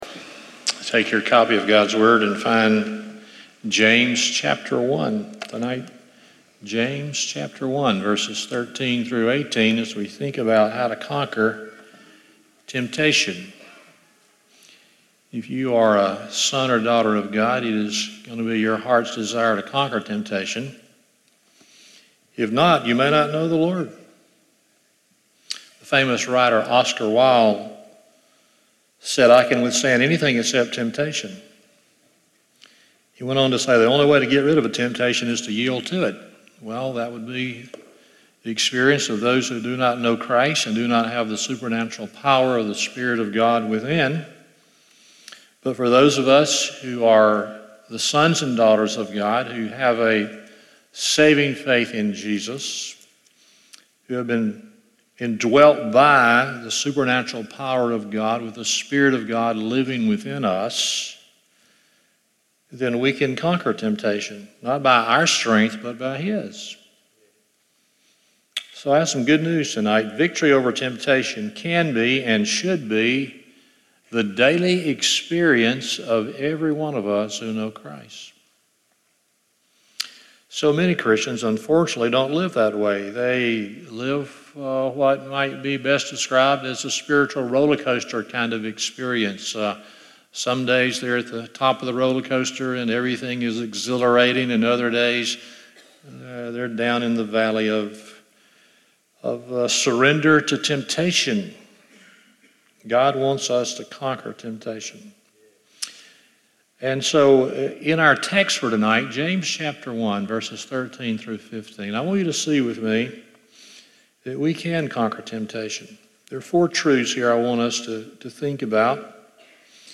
James 1:13-18 Service Type: Sunday Evening 1.